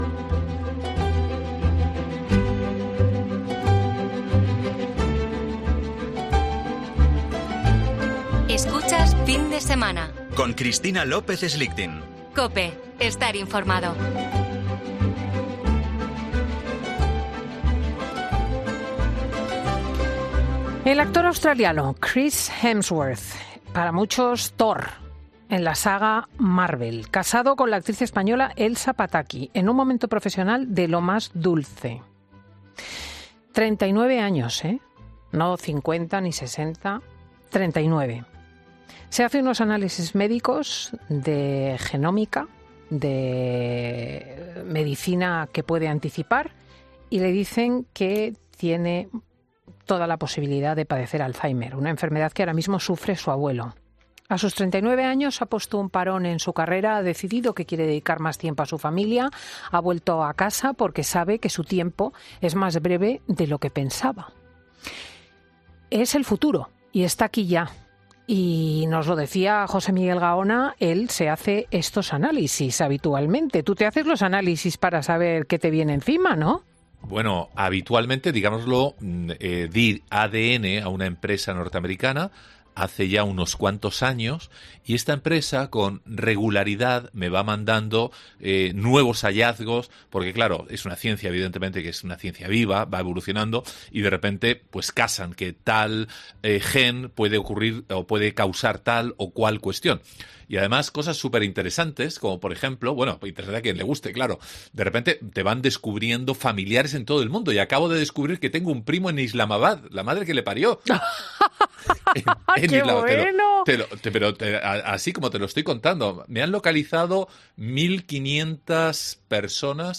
Lo abordamos en la Tertulia